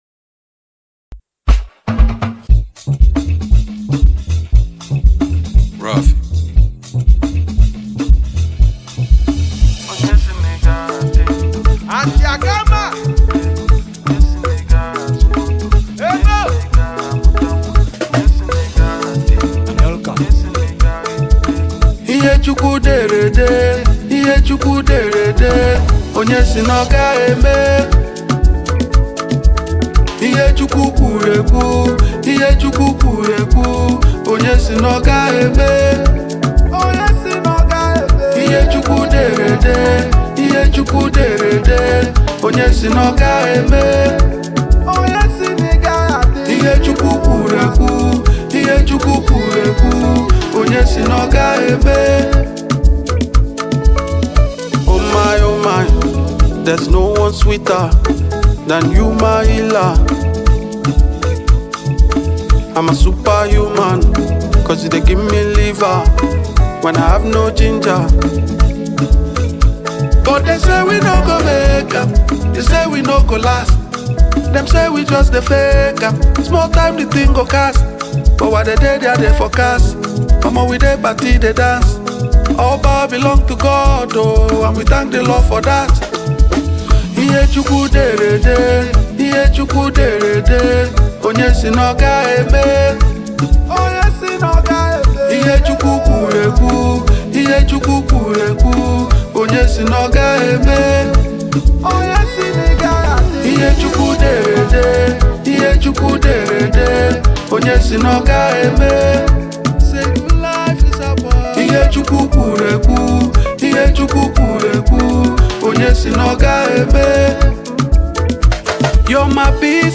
a good highlife tune